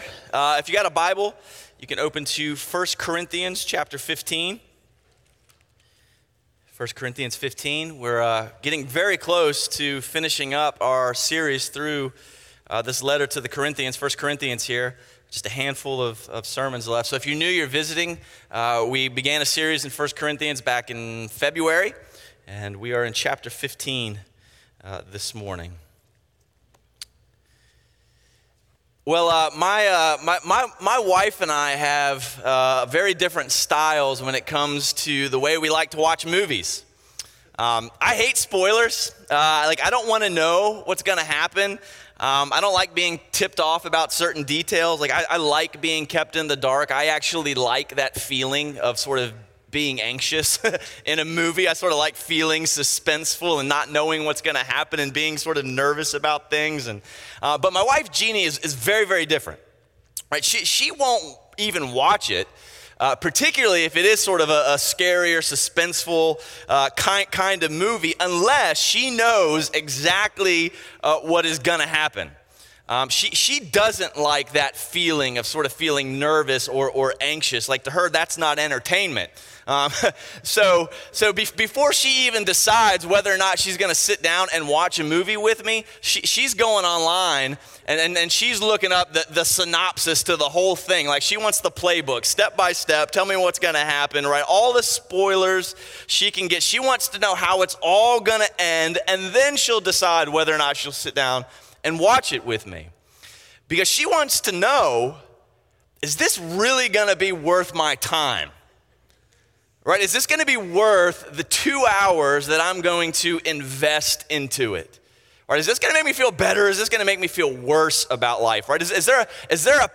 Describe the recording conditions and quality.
A Sunday morning series on 1 Corinthians at Crossway Community Church.